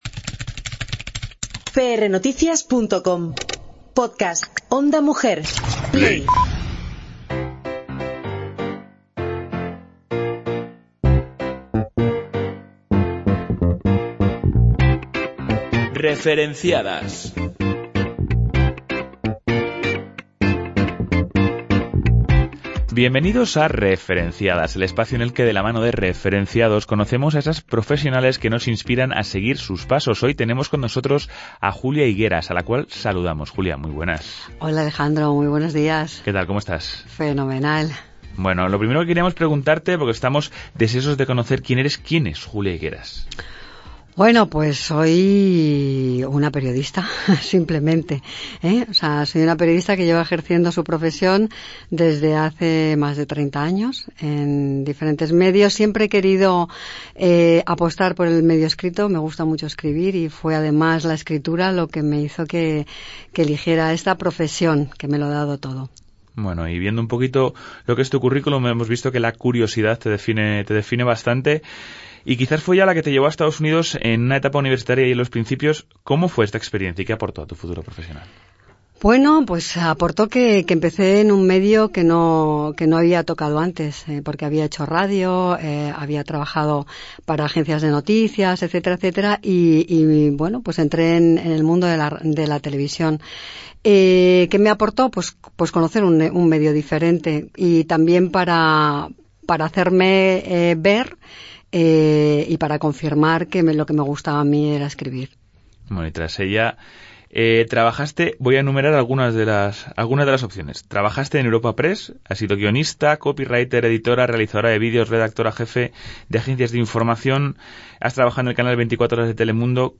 Toda la entrevista, ampliada, la encontrarás en nuestro Podcast.